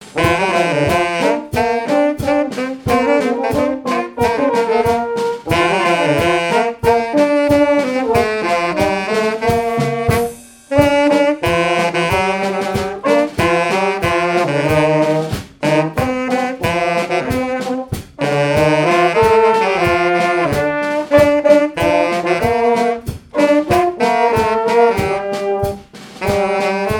gestuel : à marcher
circonstance : fiançaille, noce
Répertoire pour un bal et marches nuptiales
Pièce musicale inédite